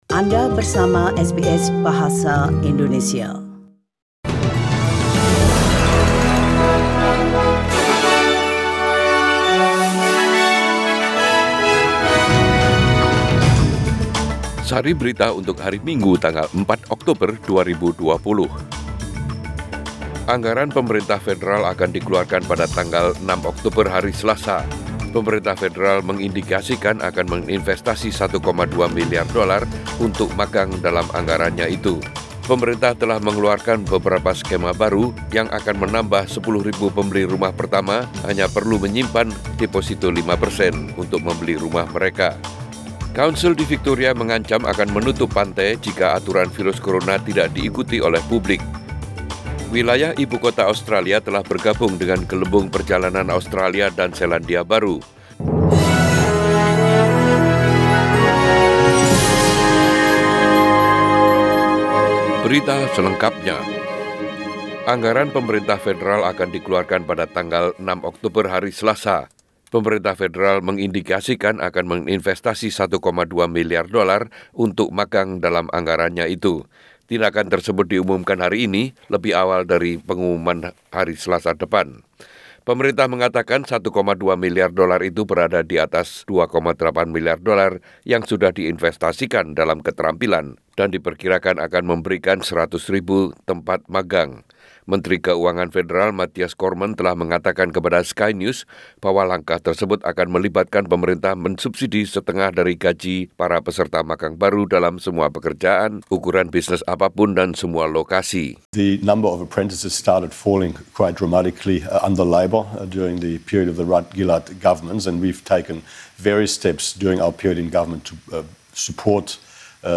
SBS Radio News in Indonesian - 4 October 2020